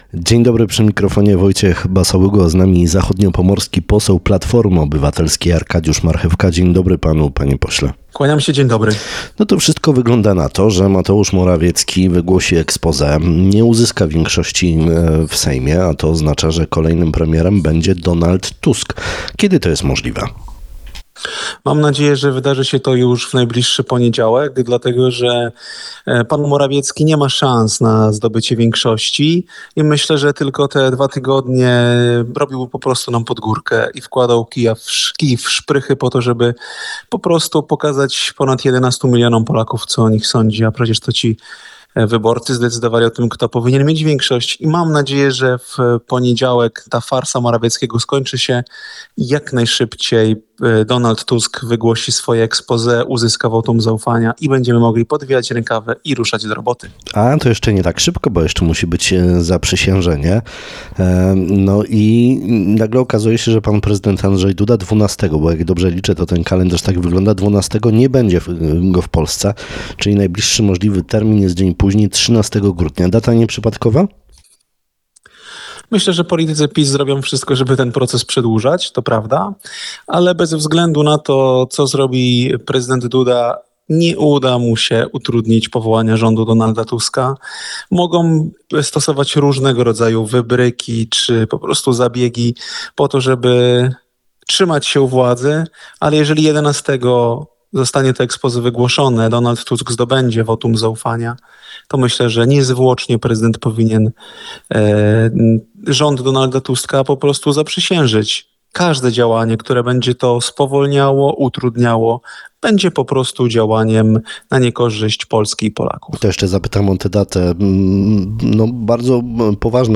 – Będzie nowy wojewoda, będą nowi przedstawiciele instytucji, będą – mam nadzieję – przedstawiciele rządu z woj. zachodniopomorskiego, co pozwoli nam mówić silnym głosem i walczyć o sprawy, które dotyczą naszego regionu – mówi Arkadiusz Marchewka, zachodniopomorski poseł PO.
Arkadiusz Marchewka był Gościem środowej Rozmowy Dnia. Był pytany także o to czy nowy wiceminister odpowiedzialny za gospodarkę morską powinien pochodzić z Pomorza Zachodniego.